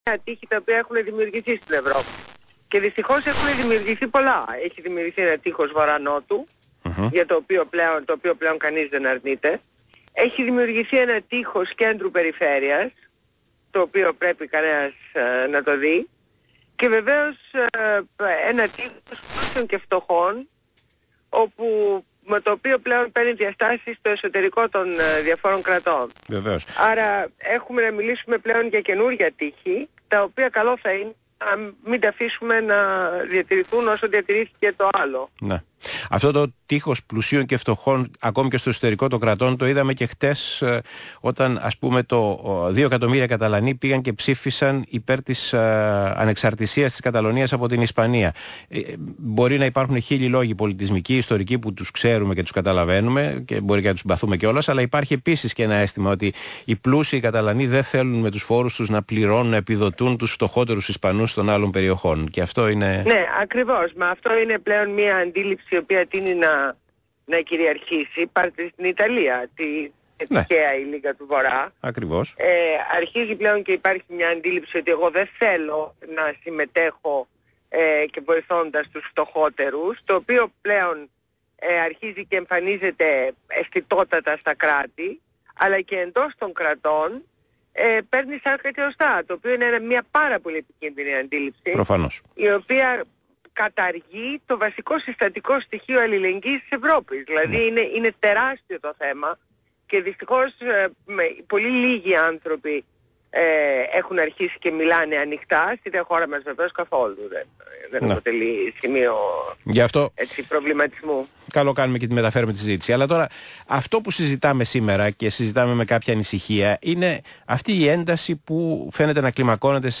Συνέντευξη στο ραδιόφωνο ΣΚΑΙ 100,3 στον Παύλο Τσίμα.